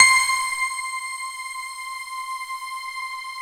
Index of /90_sSampleCDs/Syntec - Wall of Sounds VOL-2/JV-1080/SMALL-PIANO
BELLS PNO LM 10.wav